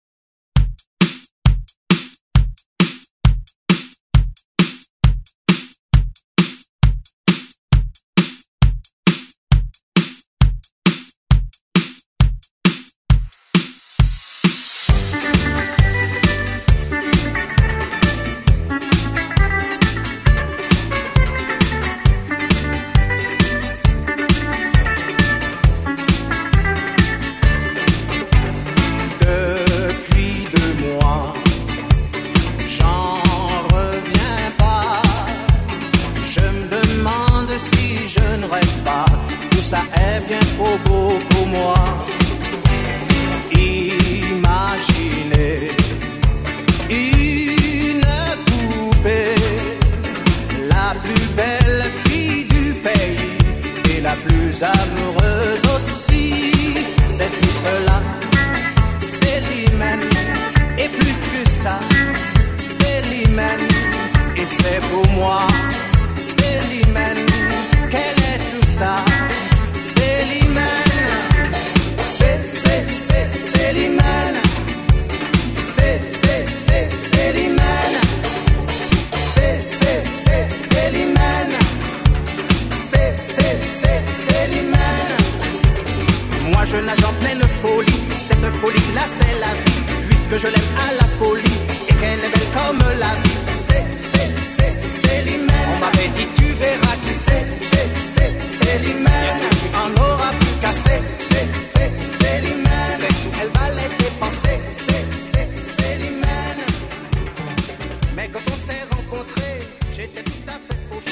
70's , LATIN , RE-DRUM 134